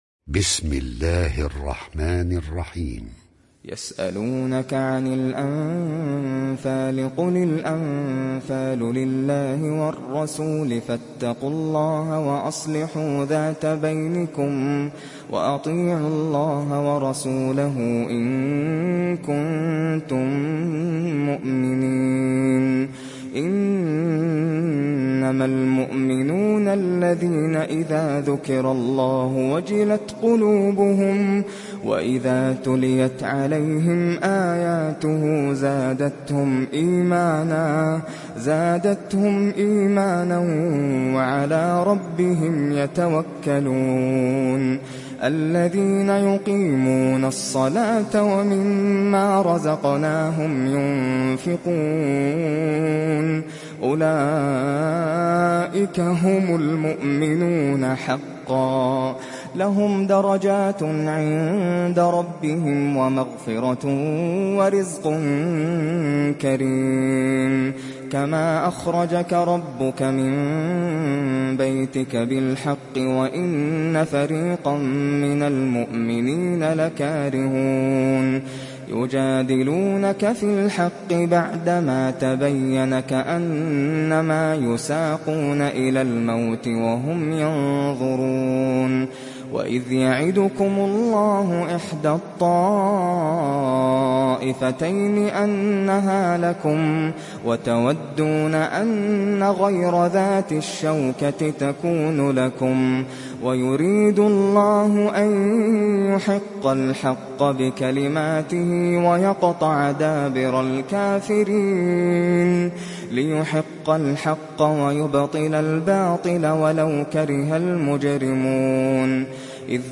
تحميل سورة الأنفال mp3 بصوت ناصر القطامي برواية حفص عن عاصم, تحميل استماع القرآن الكريم على الجوال mp3 كاملا بروابط مباشرة وسريعة